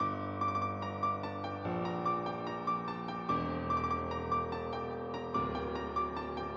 Tag: 146 bpm Trap Loops Piano Loops 1.11 MB wav Key : F